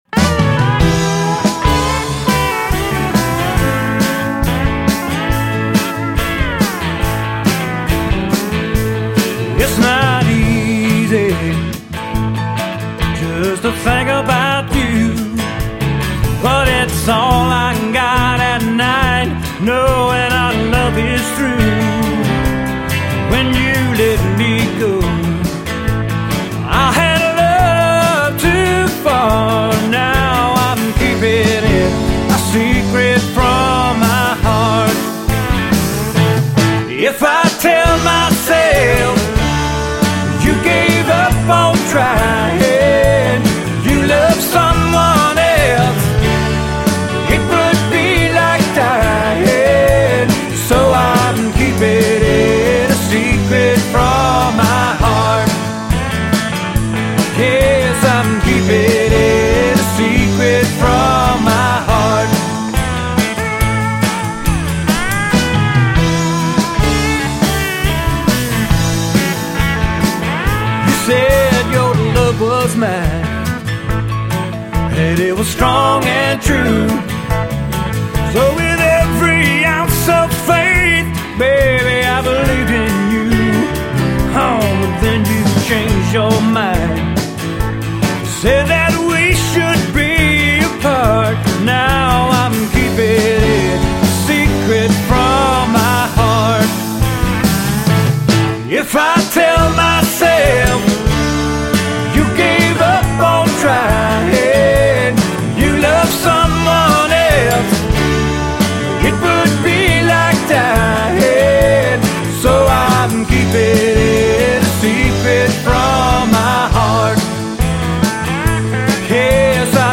Original Mountain Rock From Cashiers